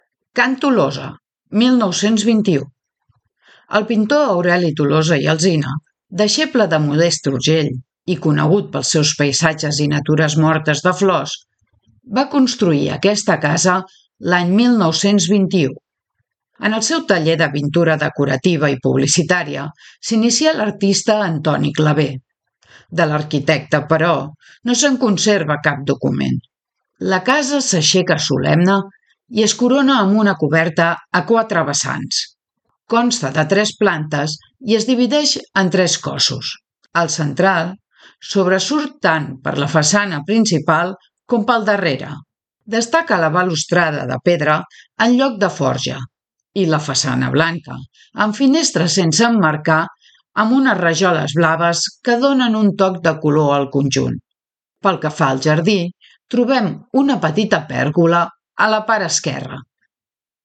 • RUTA MODERNISTA AUDIOGUIADA